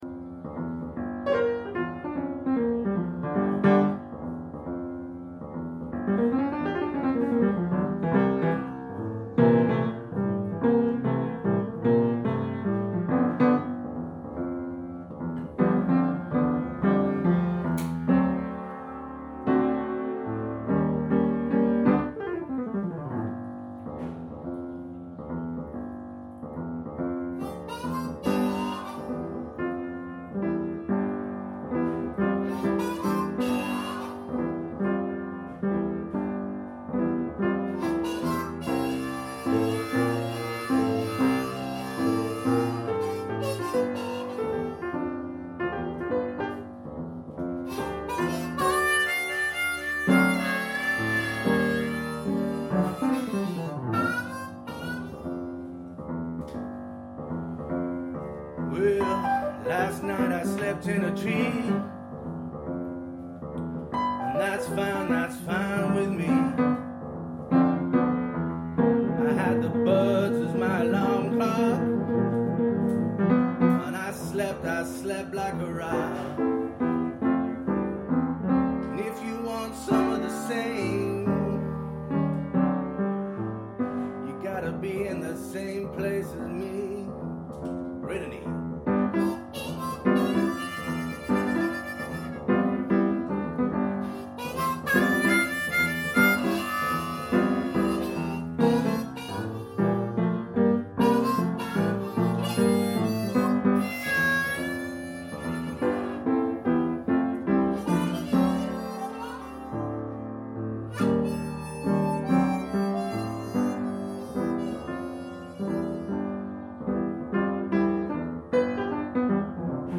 An impromptu breakfast blues session. 'Tree House Blues'.